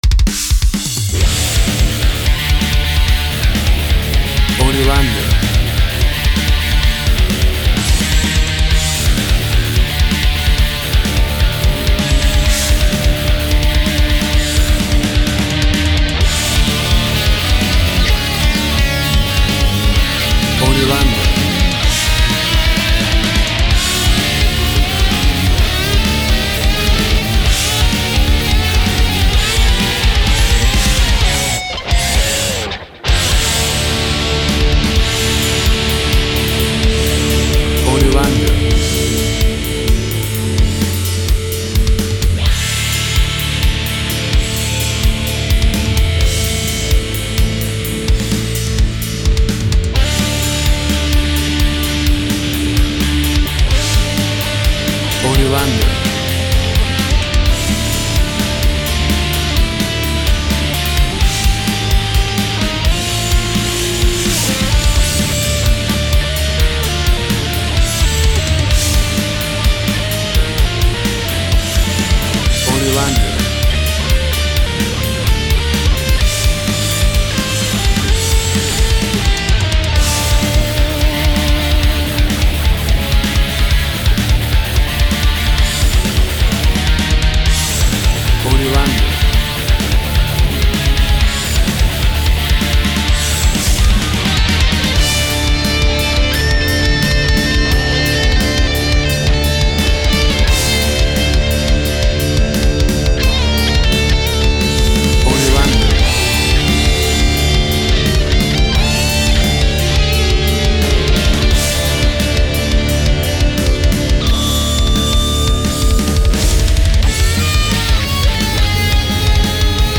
Hard Metal Rock.
Tempo (BPM) 130